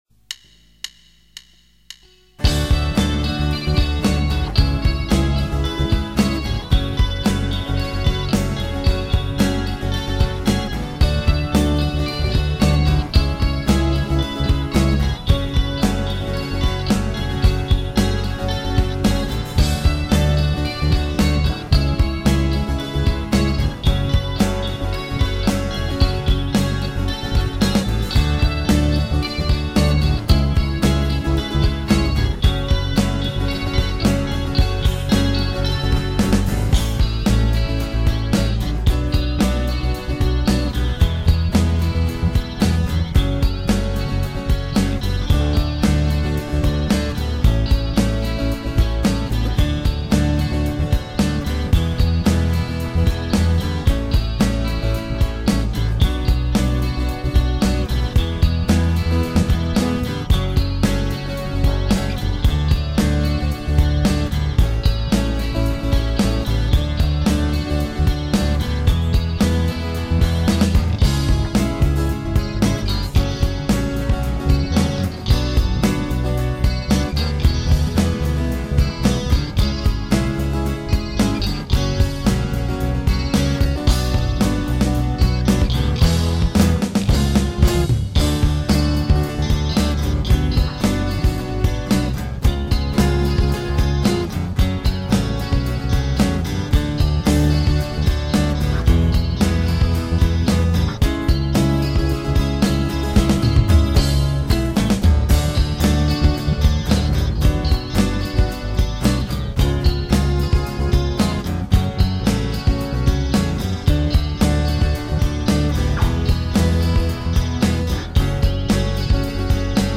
ＴR1 Drum ＭＩＤＩ　data
TR2 A-Giter
TR3 A-Giter
TR4 E-Giter
ドラムを打ち込もうとおもったらMIDIデータが あったの使わせてもらいました。
印象的なイントロのエレキのフレーズは手が腱鞘炎になるほど 練習しました。